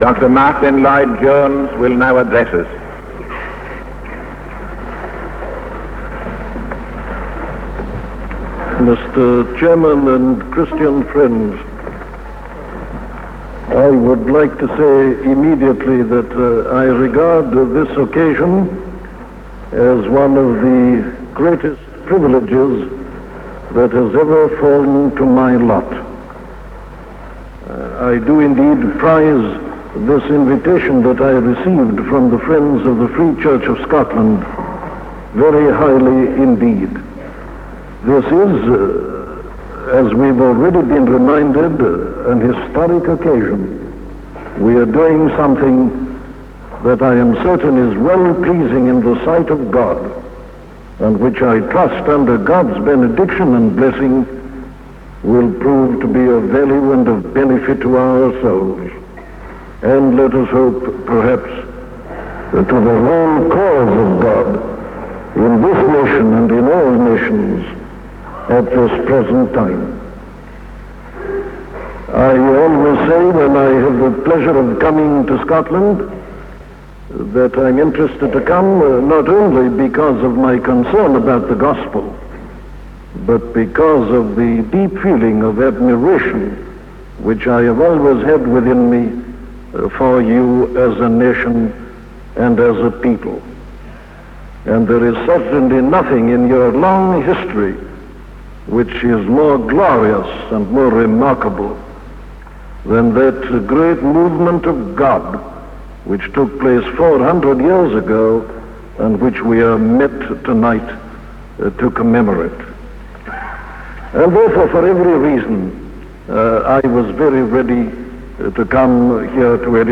Scottish Reformation - a sermon from Dr. Martyn Lloyd Jones
In Dr. Martyn Lloyd-Jones’ sermon on the Scottish Reformation, delivered at Usher Hall in Edinburgh in April 1960to mark the 400th anniversary of the Scottish Reformation, he emphasizes the importance of looking to our past for answers in the present.